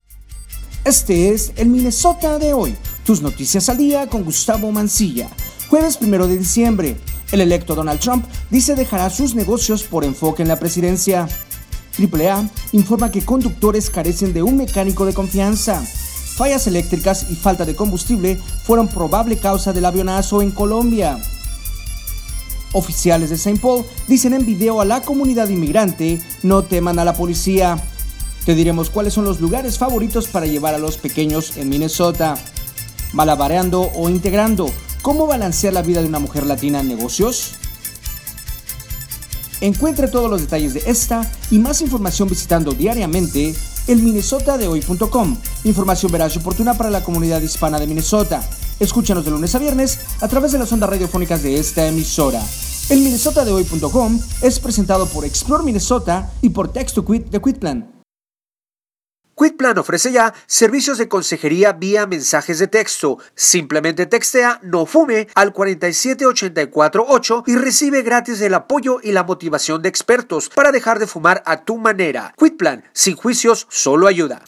MNHoy Radio Capsula